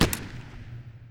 SMG1_Shoot 03.wav